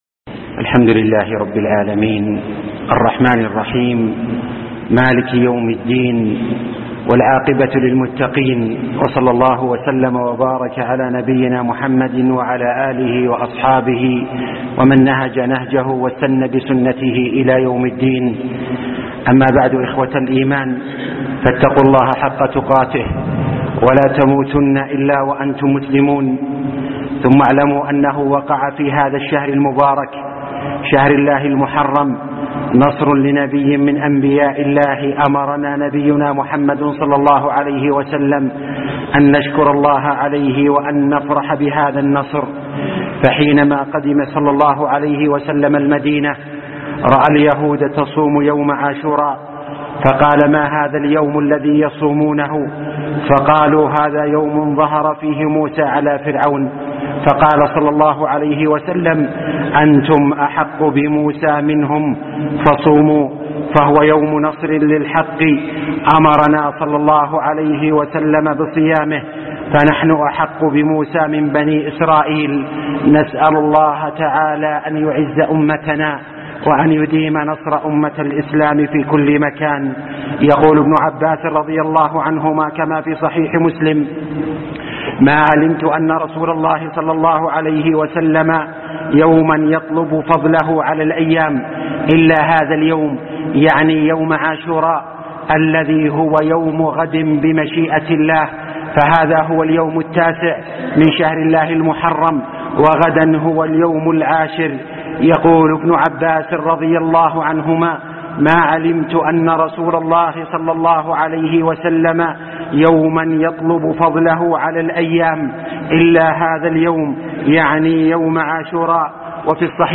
العاشر والتميز - خطب الجمعة